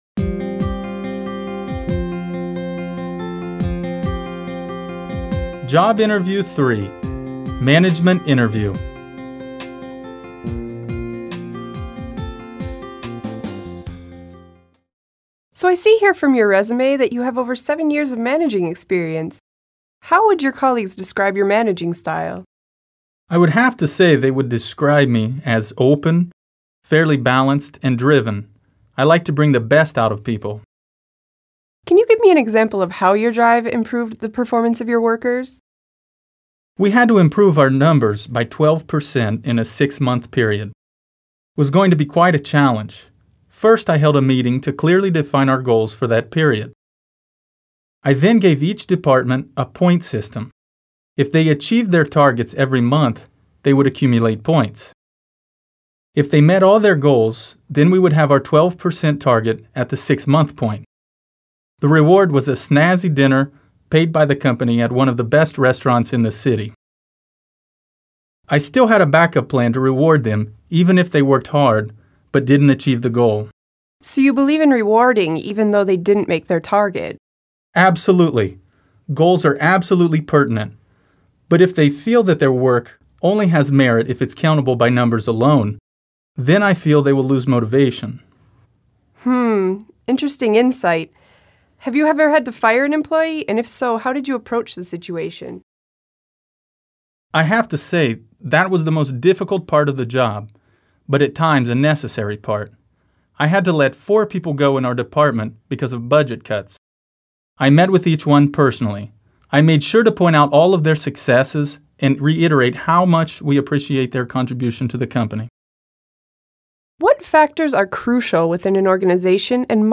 I –MANAGEMENT JOB INTERVIEW  (LISTENING INPUT)
Management Interview